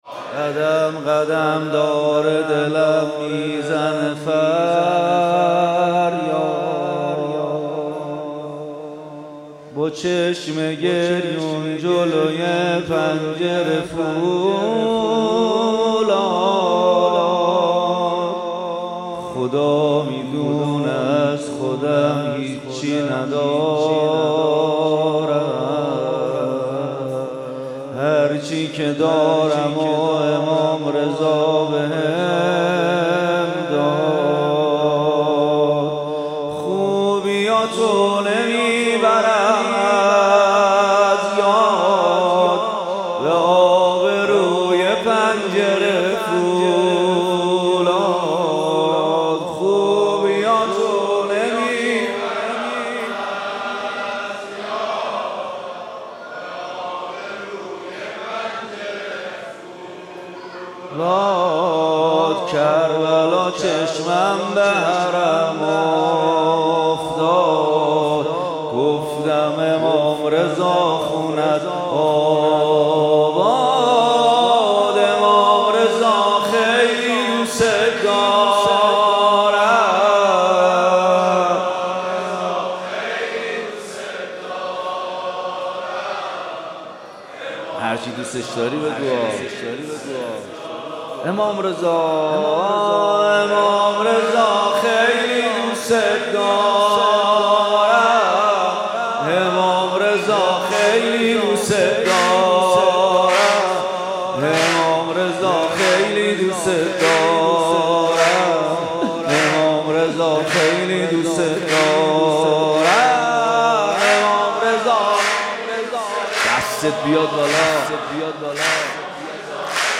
سرود مولودی